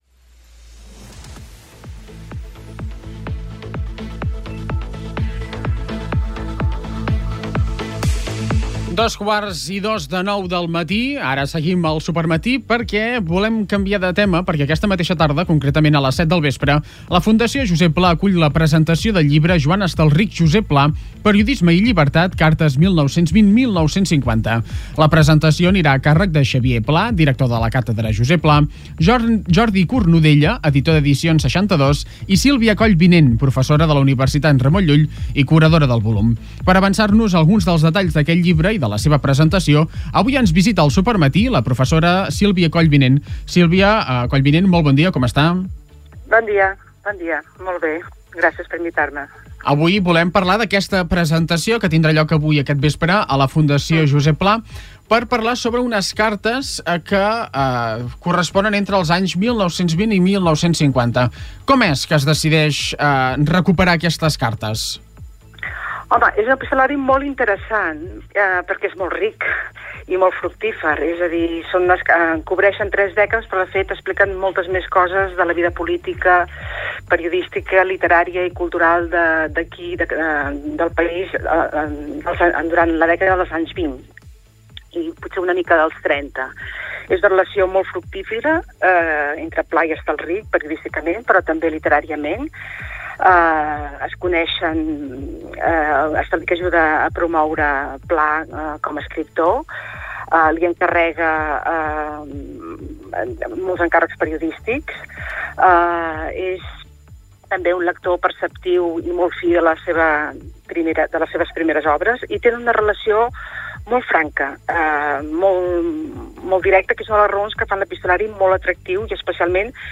ENTREVISTA-JOSEP-PLA.mp3